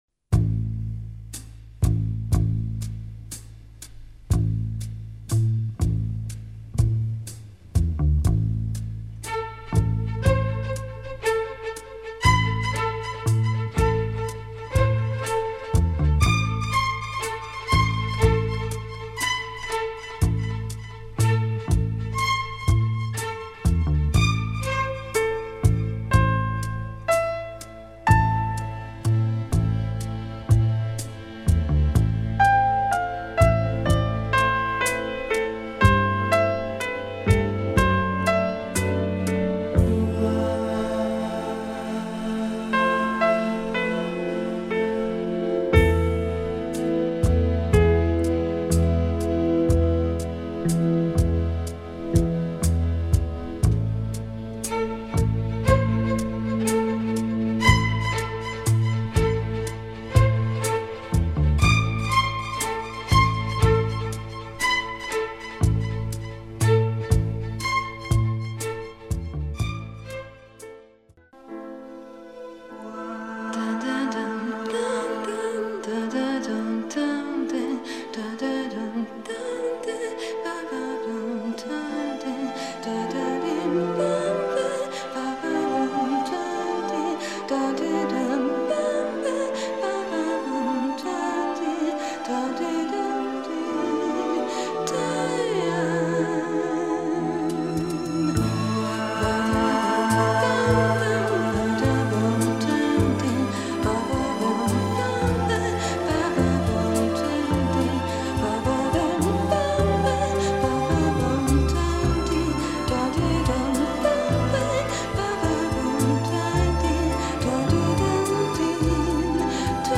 Soft groove and wordless female vocals